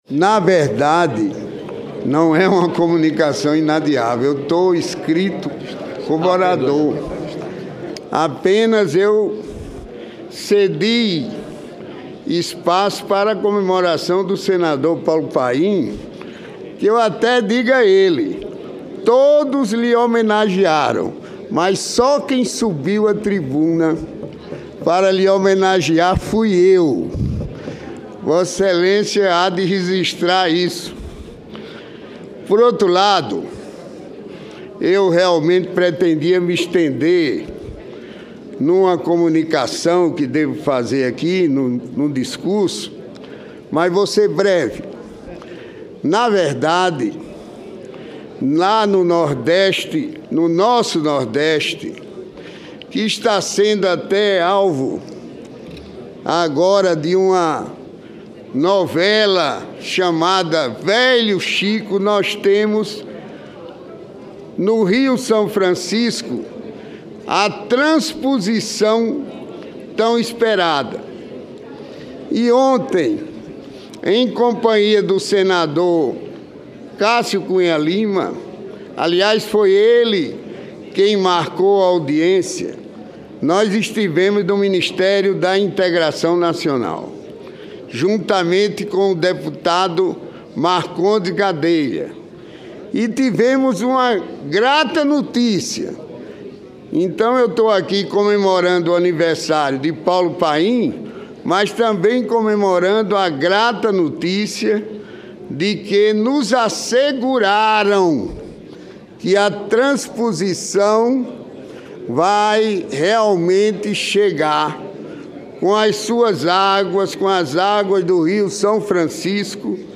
Plenário 2016
Discursos